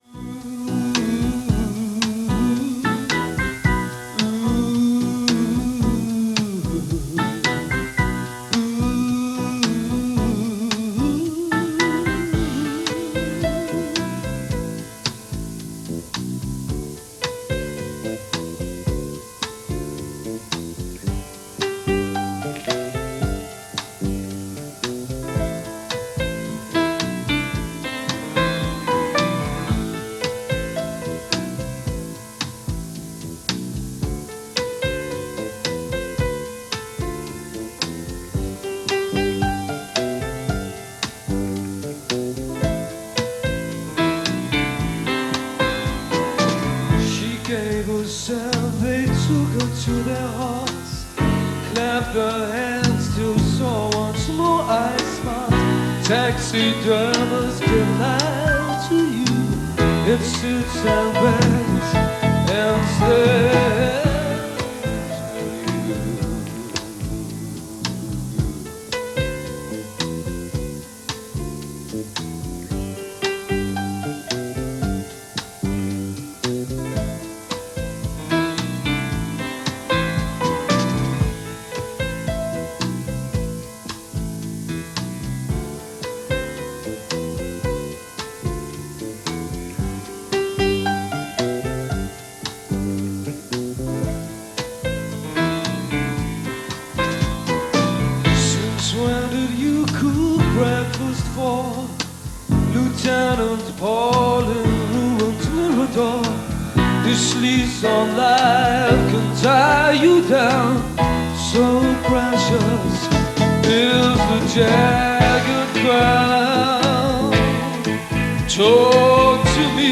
80s New Wave/Funk/R&B/Mashup